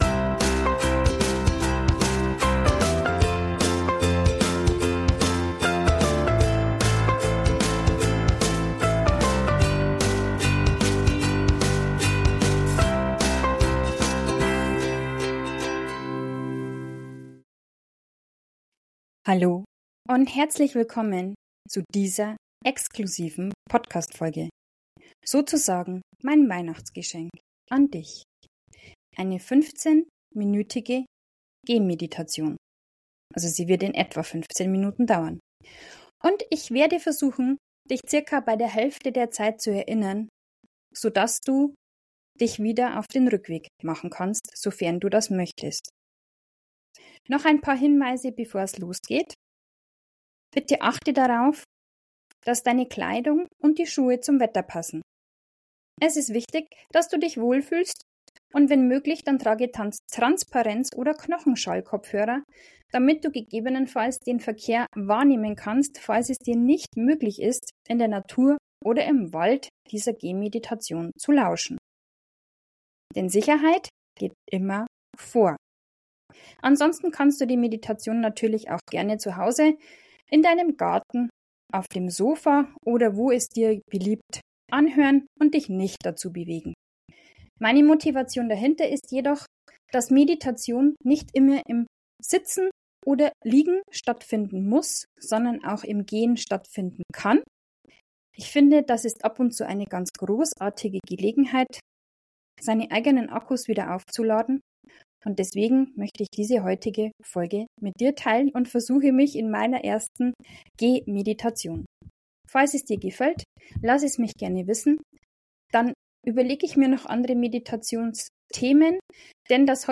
068_Gehmeditation.mp3